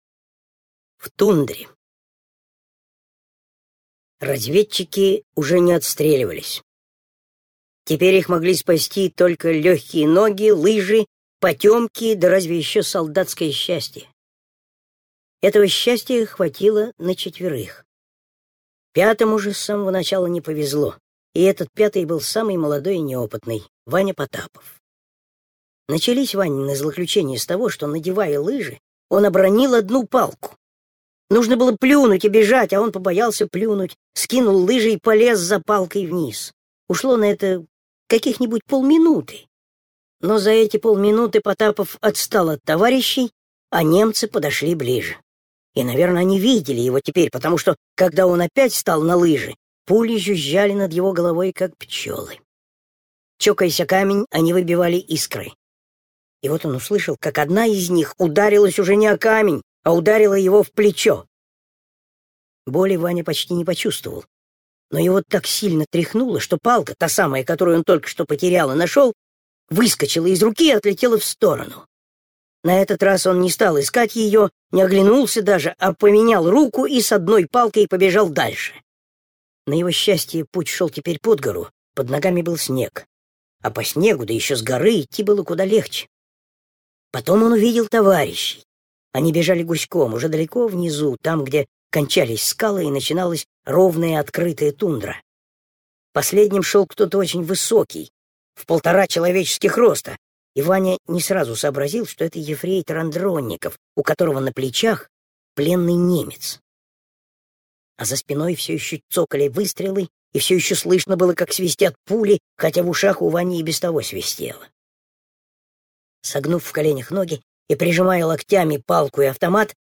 В тундре - аудио рассказ Пантелеева - слушать онлайн